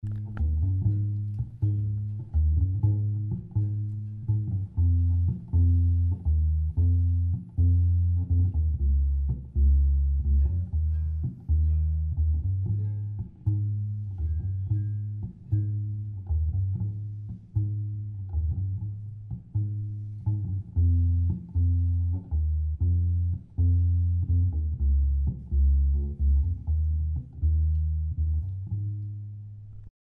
This is the primavera semi-hollow bass on the
SemiHollow4_clip1.mp3